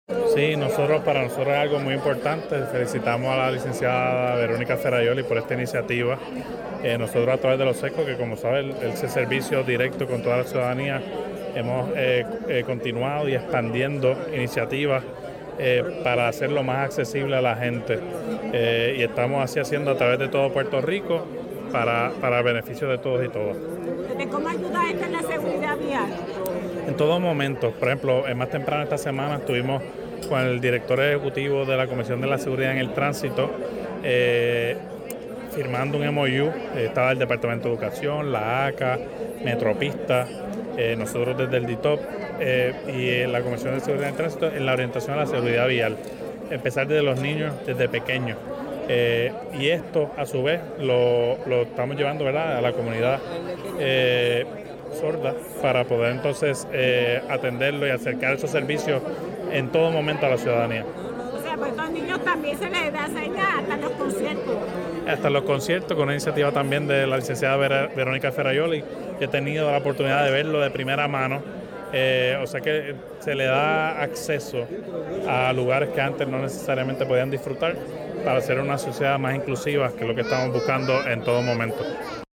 Titular del DTOP durante la apertura de la exhibición “Siente el Ritmo” dijo que el Programa se utiliza en los CESCOS que es un servicio directo a la ciudadanía (sonido)